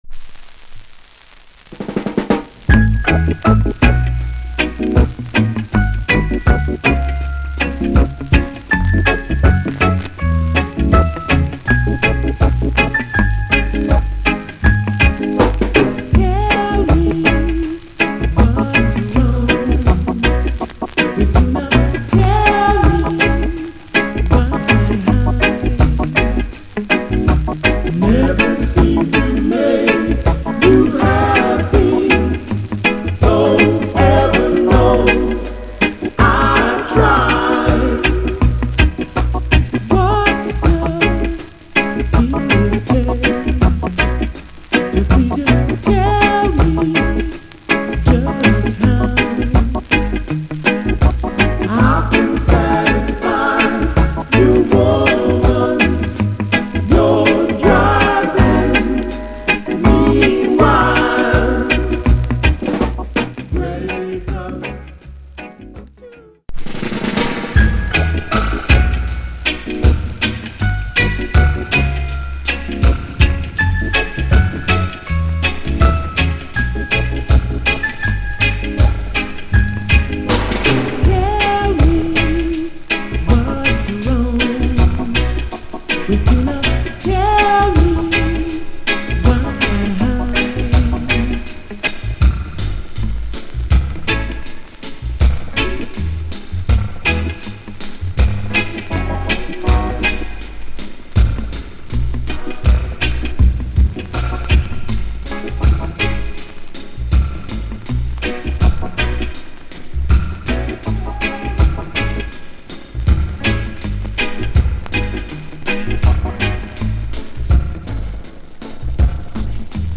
ホーンズが入っていない、ジャマイカ盤とは違うテイク。